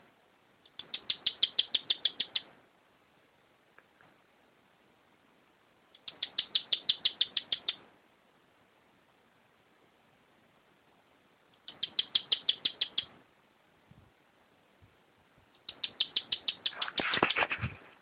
Bandurrita Chaqueña (Tarphonomus certhioides)
Nombre en inglés: Chaco Earthcreeper
Fase de la vida: Adulto
Localidad o área protegida: Parque Provincial Chancaní
Condición: Silvestre
Certeza: Observada, Vocalización Grabada